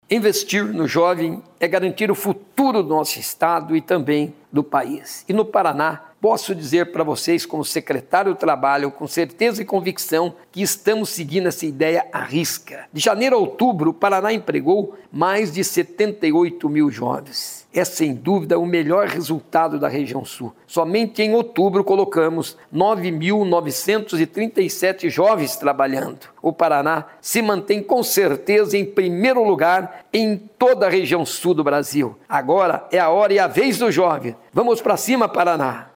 Sonora do secretário do Trabalho, Qualificação e Renda, Mauro Moraes, sobre o Paraná liderar a empregabilidade de jovens na região Sul em 2023